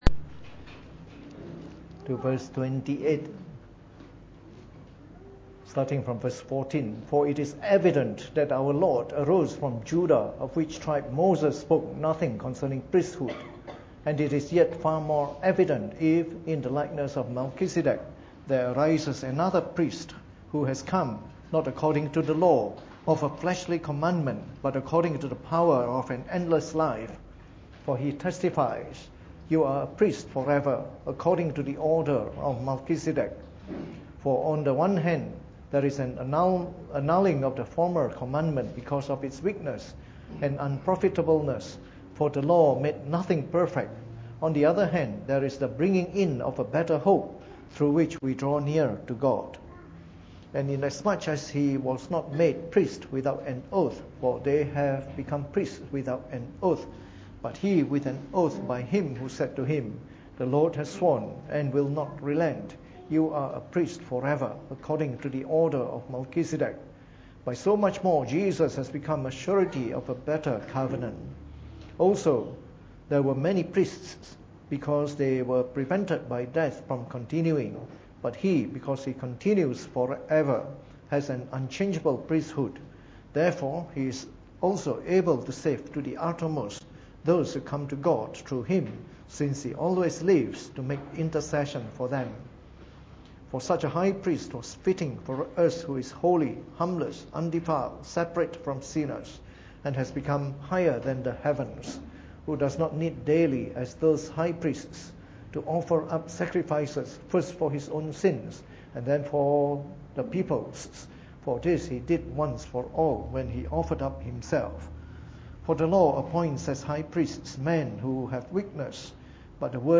Preached on the 15th of March 2017 during the Bible Study, from our series on Semper Reformanda.